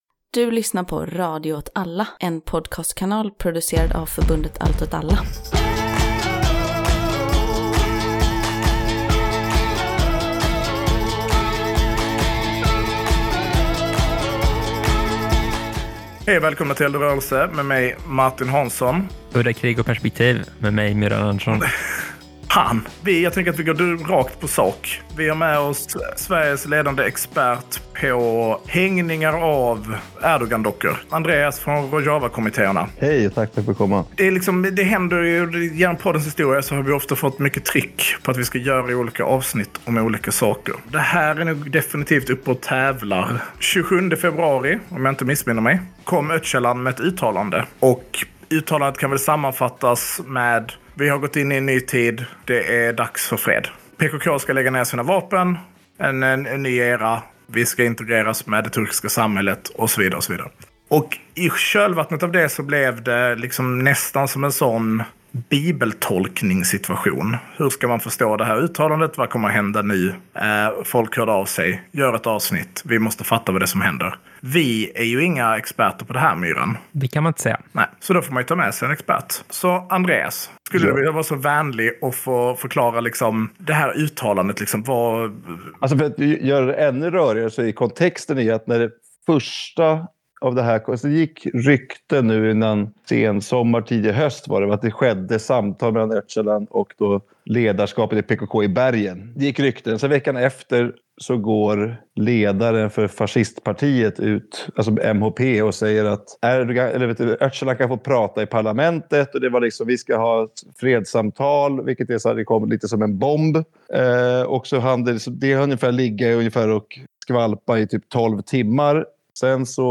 eld-och-rorelse-158-lagger-pkk-ner-vapnen-intervju-med-rojavakommitteerna.mp3